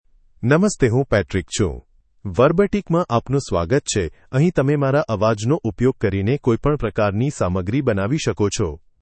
MaleGujarati (India)
PatrickMale Gujarati AI voice
Patrick is a male AI voice for Gujarati (India).
Voice sample
Listen to Patrick's male Gujarati voice.
Male